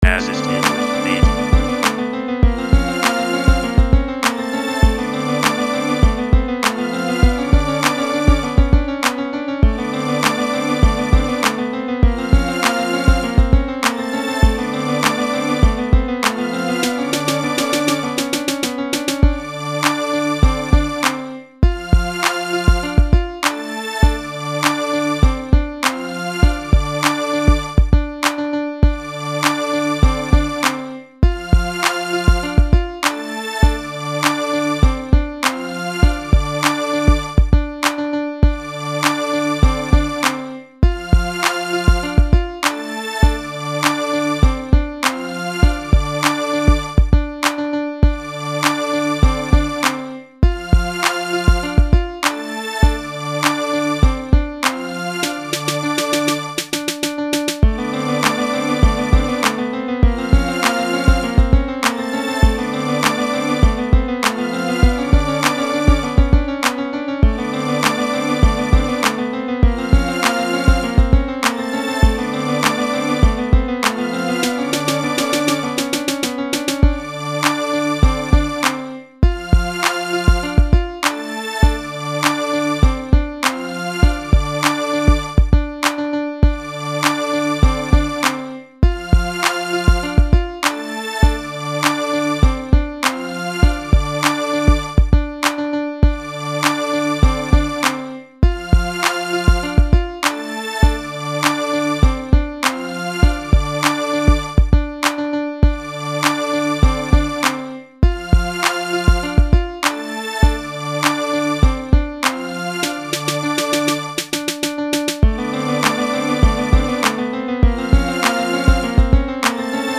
자작비트 하나 올려봅니다 | 리드머 - 대한민국 힙합/알앤비 미디어
FL스투디오로 작업하셨군요 ㅋ 첫작치고는 매우 좋아요!